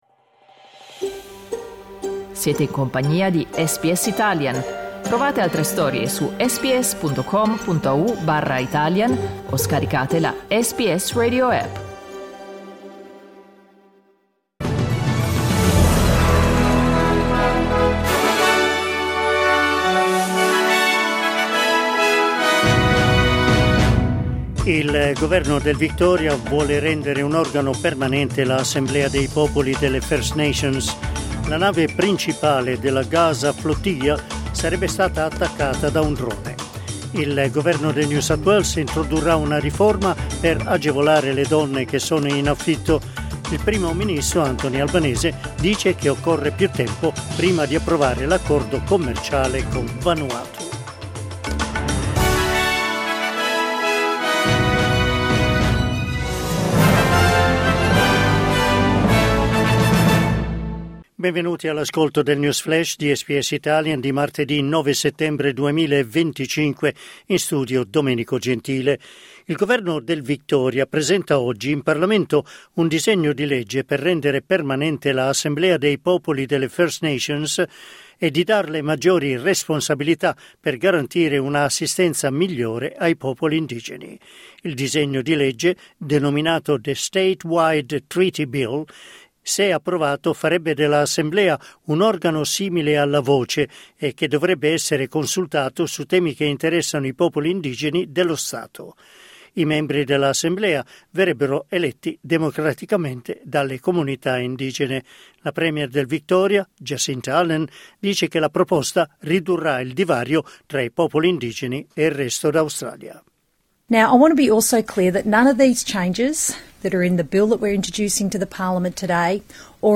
News flash martedì 9 settembre 2025
L’aggiornamento delle notizie di SBS Italian.